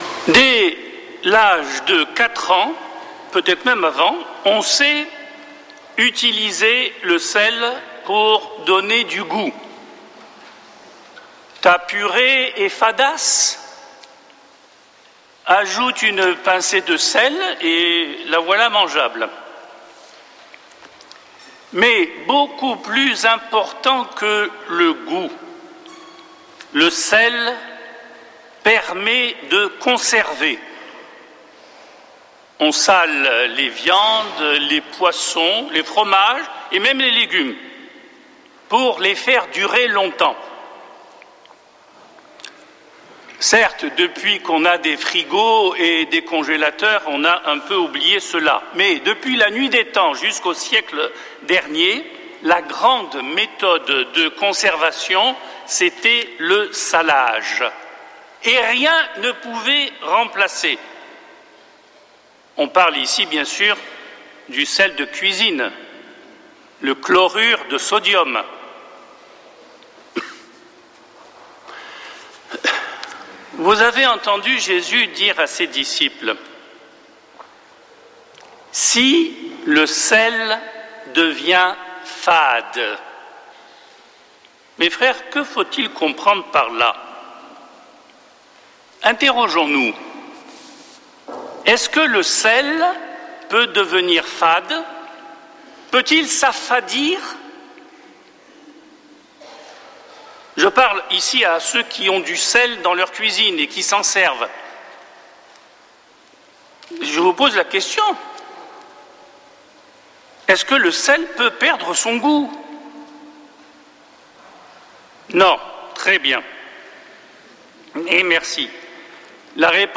dimanche 8 février 2026 Messe depuis le couvent des Dominicains de Toulouse Durée 01 h 28 min